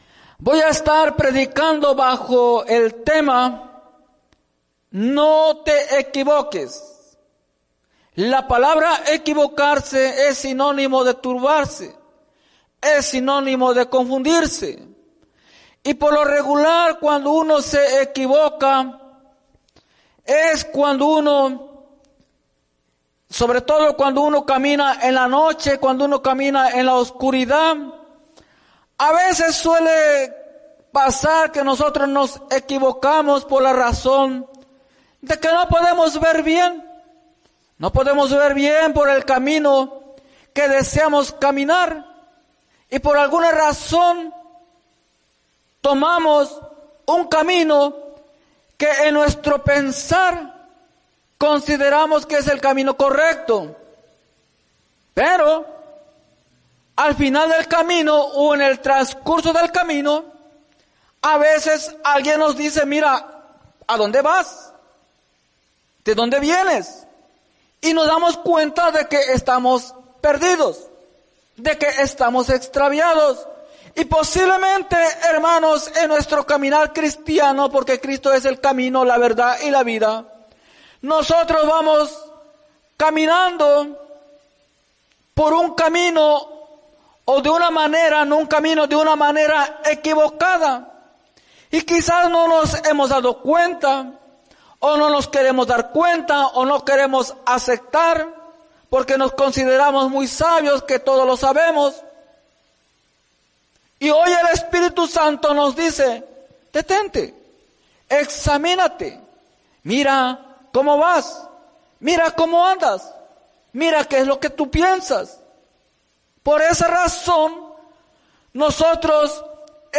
Mensaje
en la Iglesia Misión Evangélica en Noristown, PA